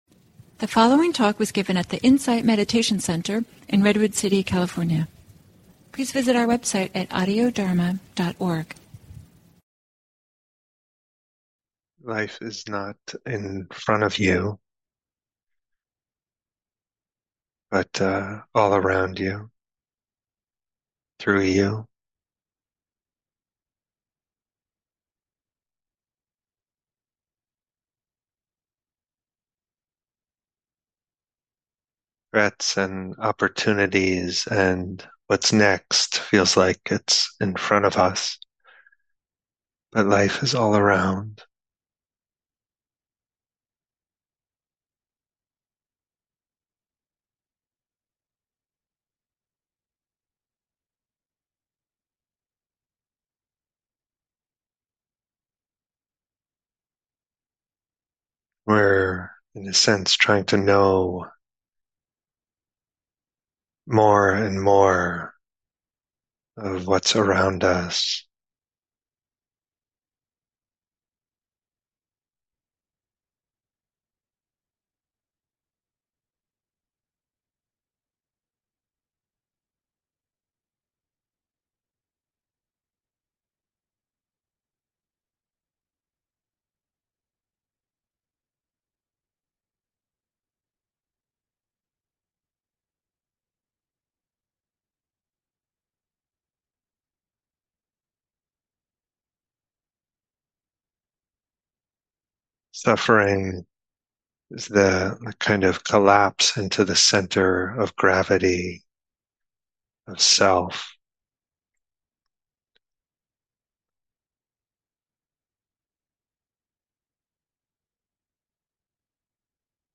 Guided Meditation: Bright, Steady, Open